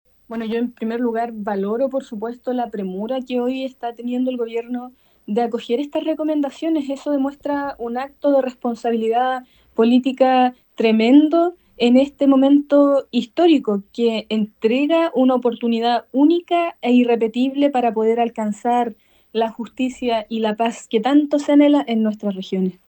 Estos anuncios fueron valorados por la diputada oficialista del Frente Amplio, Ericka Ñanco. Ella indicó que representan “un acto de responsabilidad política”.